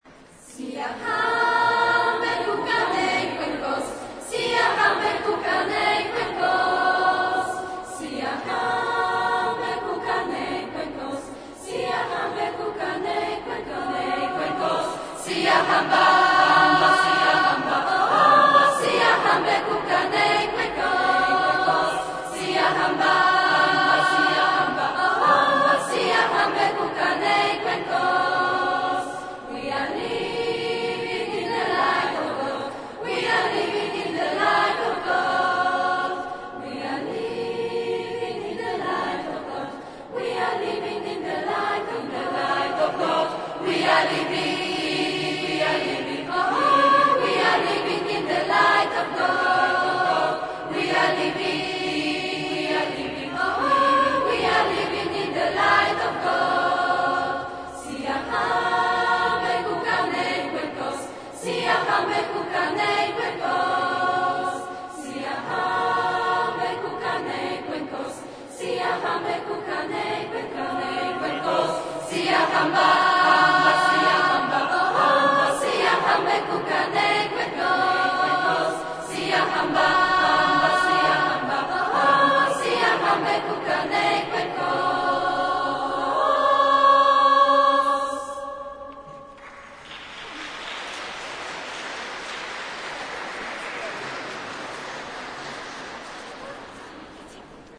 Oto dwa nagrania muzyczne świadczące o możliwościach artystycznych młodzieży i jej pracy nad kształtowaniem własnych możliwości głosowych.
Nagranie chóru II
Chor2.mp3